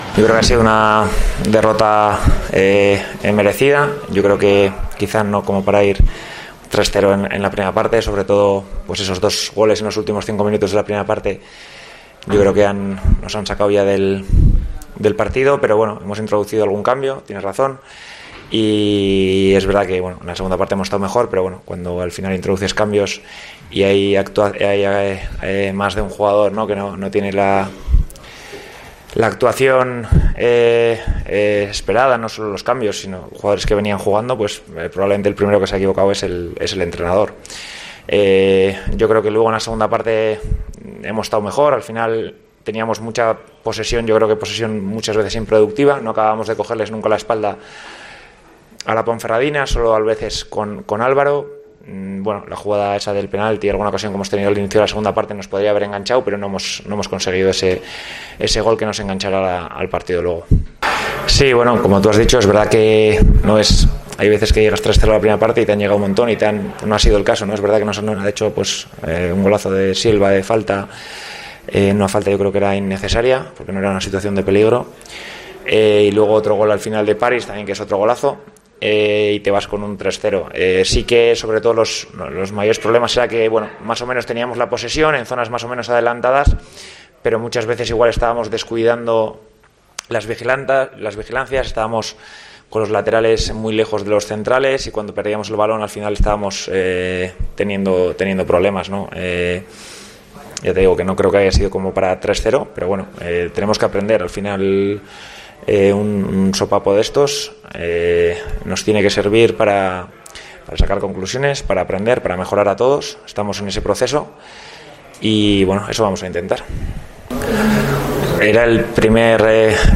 AUDIO: Escucha aquí las palabras de entrenador del Rayo Vallecano tras perder 3-0 en El Toralín ante la Deportiva Ponferradina